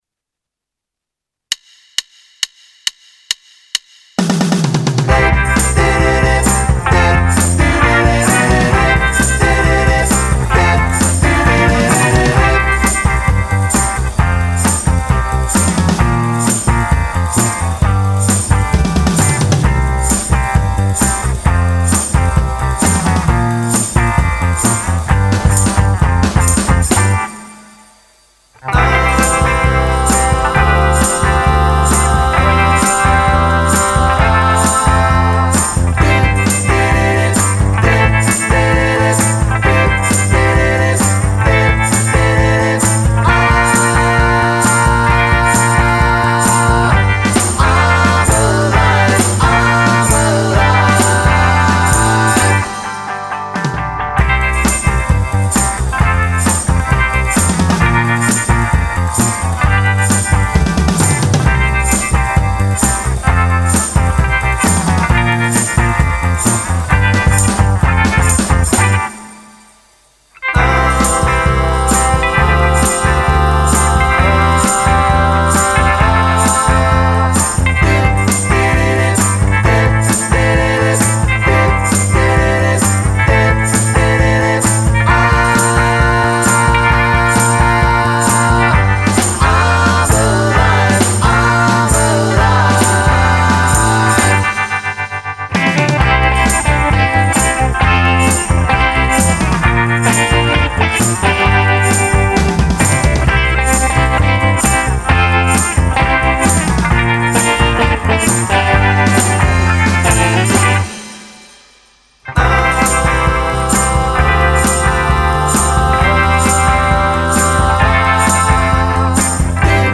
I’m Alive (click intro) | Ipswich Hospital Community Choir